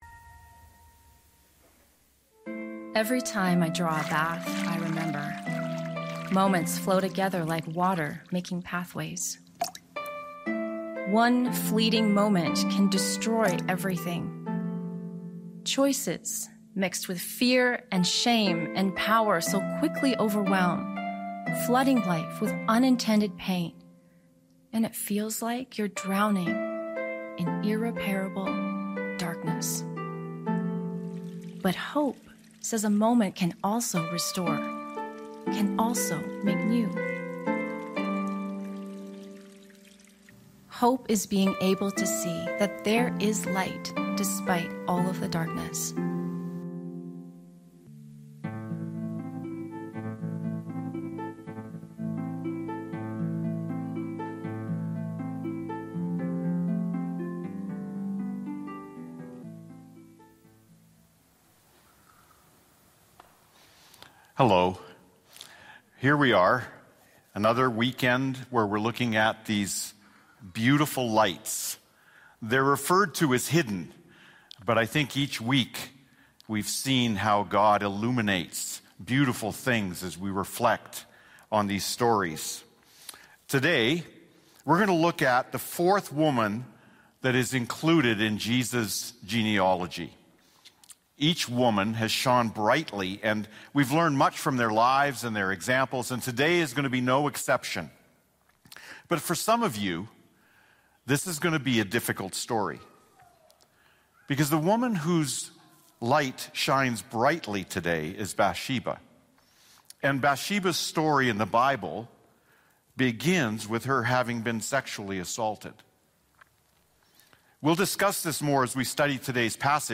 English Teaching MP3 This Weekend's Scriptures...